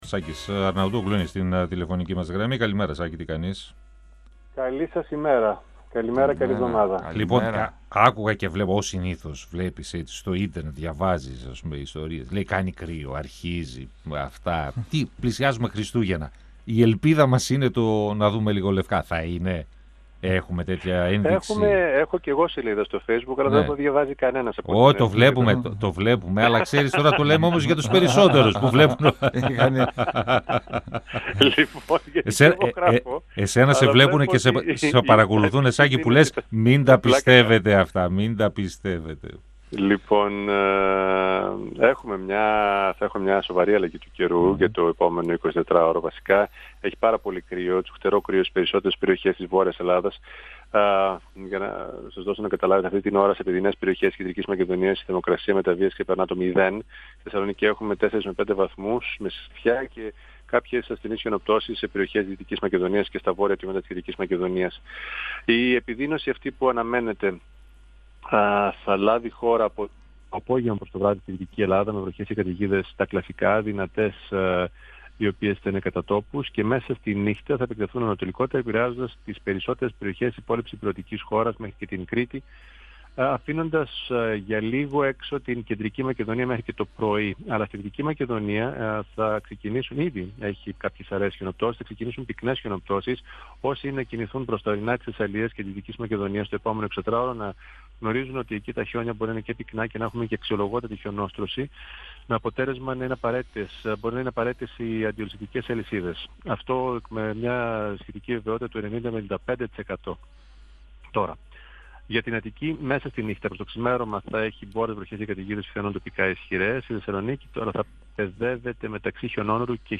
Ο Σάκης Αρναούτογλου, στον 102FM του Ρ.Σ.Μ. της ΕΡΤ3